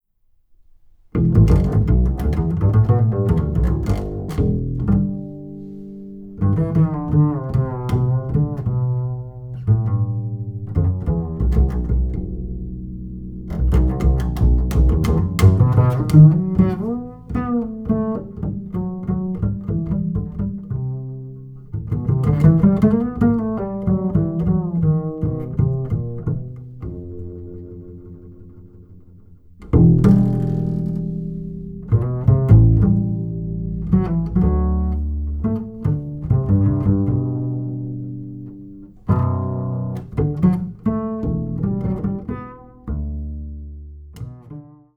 楽器：カザリーニ1924年イタリア製
名器Fausto Casaliniと奏でる、永遠の歌。
深いベースソロの世界に静かに厳かに惹きこまれてゆく。
ウッドベースによる完全ソロ作品。